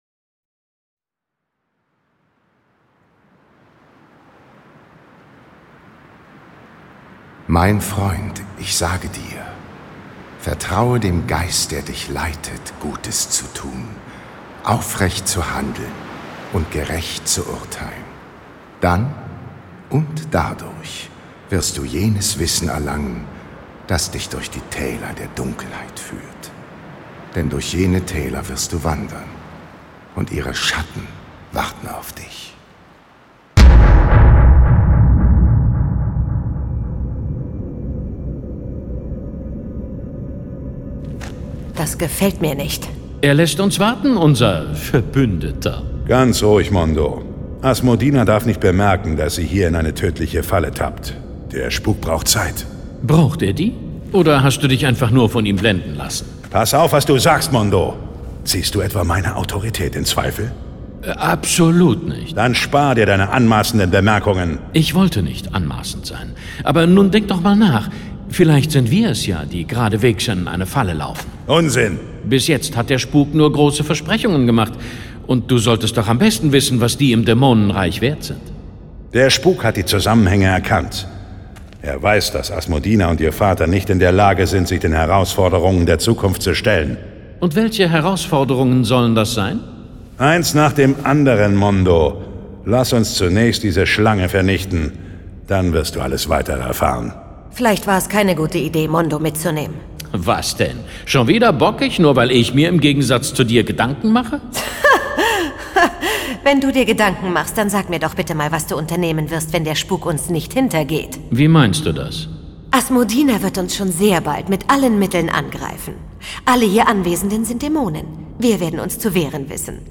John Sinclair - Folge 62 Bring mir den Kopf von Asmodina (III/III). Hörspiel.